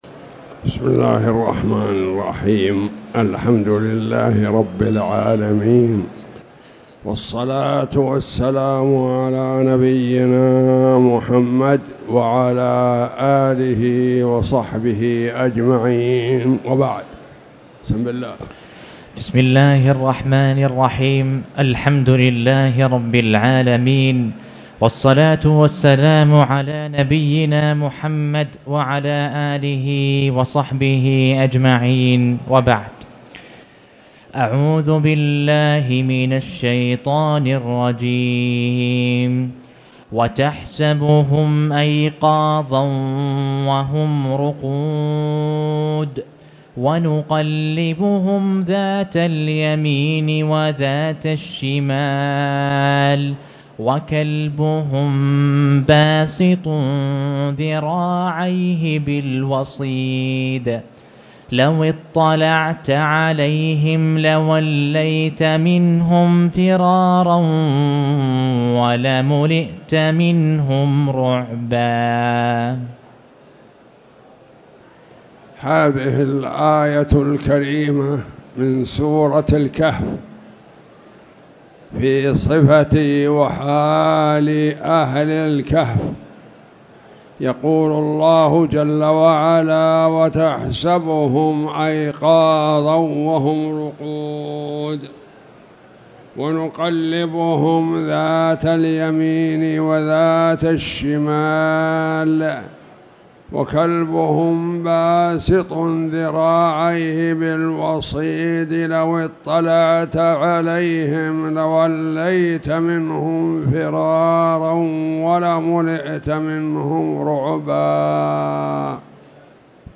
تاريخ النشر ١٦ جمادى الآخرة ١٤٣٨ هـ المكان: المسجد الحرام الشيخ